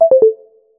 notification_sounds
stairs.mp3